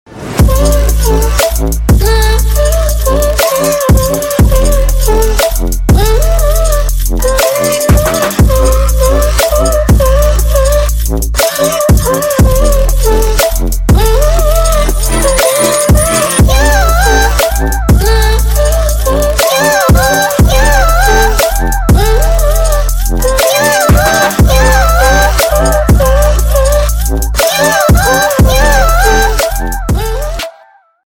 Громкие Рингтоны С Басами
Рингтоны Ремиксы » # Рингтоны Электроника